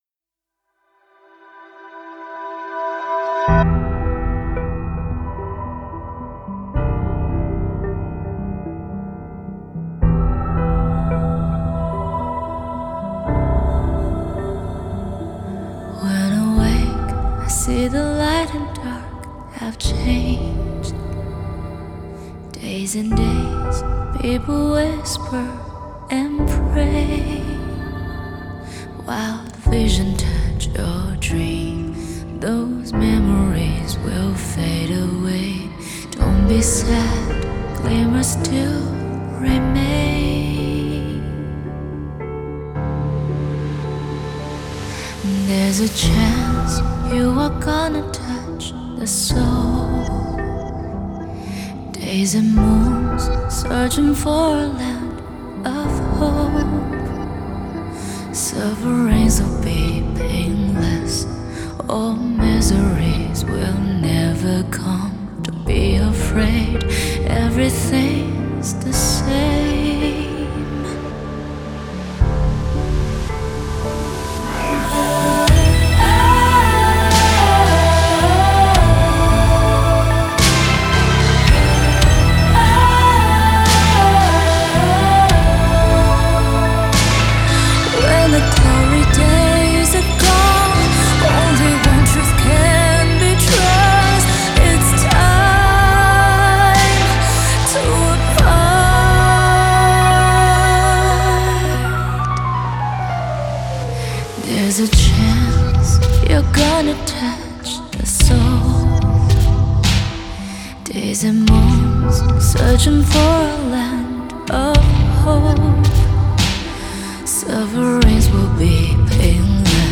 Ps：在线试听为压缩音质节选，体验无损音质请下载完整版
弦乐 String Orchestra